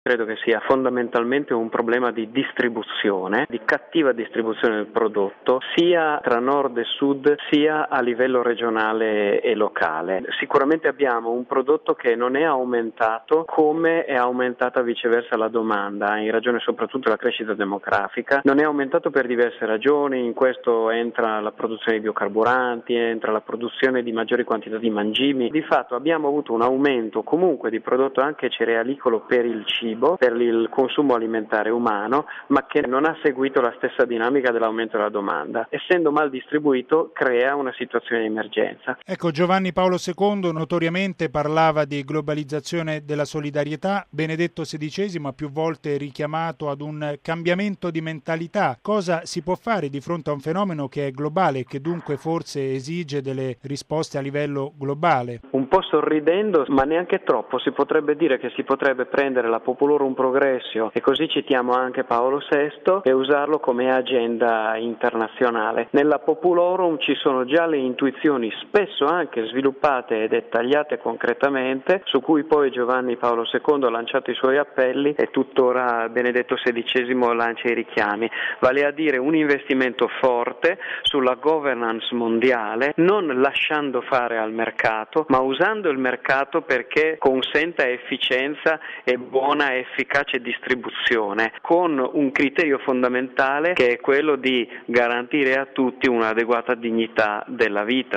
ha intervistato l’economista